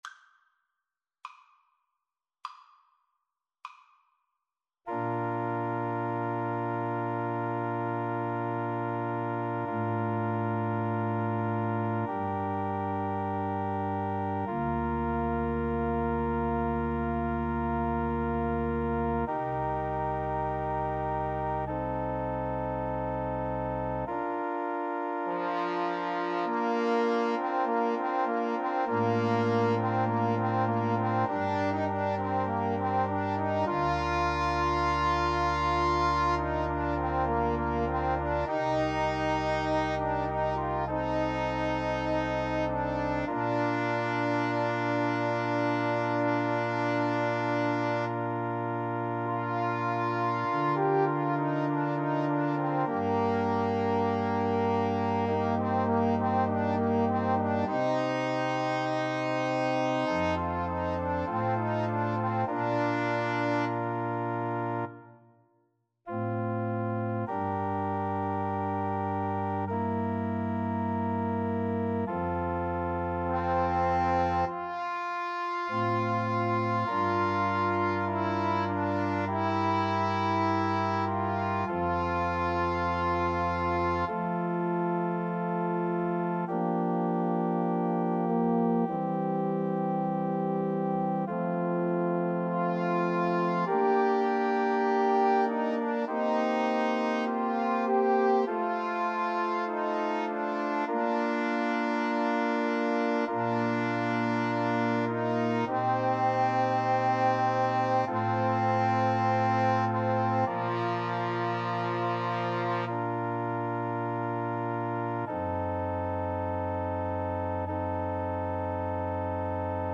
Bb major (Sounding Pitch) (View more Bb major Music for Trombone Duet )
Largo
Classical (View more Classical Trombone Duet Music)